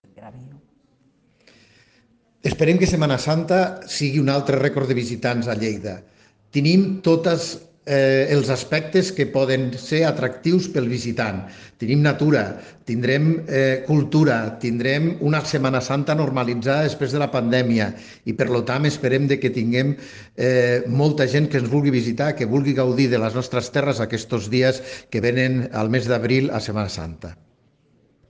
El president de Turisme de Lleida i tinent d’alcalde, Paco Cerdà, recorda que Lleida és també una ubicació idònia per a visitar-la durant aquestes vacances i confia arribar a una gran afluència de visitants amb el retorn a la normalitat. Tall de veu P. Cerdà Les propostes de promoció turística que desplegarà Turisme de Lleida permeten descobrir i conèixer el patrimoni de la ciutat.